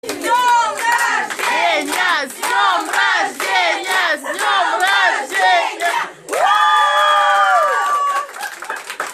Звук с криками женщин на русском языке С Днем Рождения